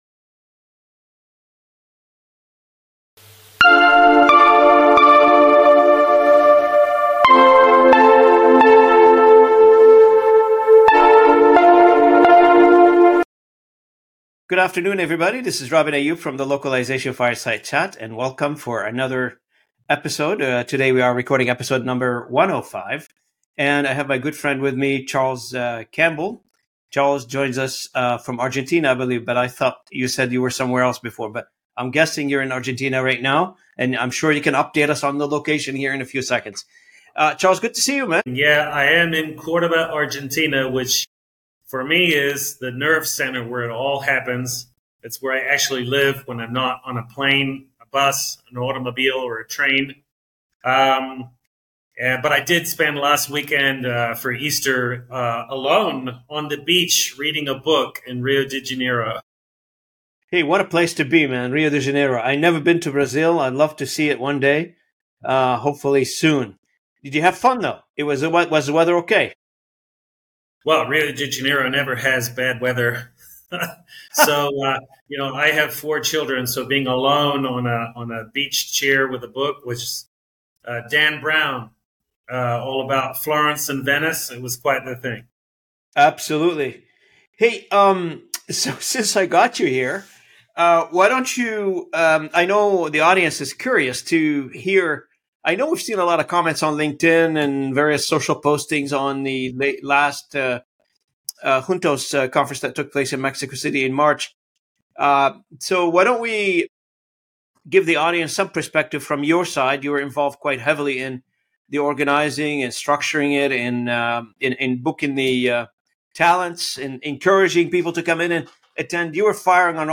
🔔 Subscribe to stay updated with more industry leader interviews and discussions on localization, AI, and global marketing trends!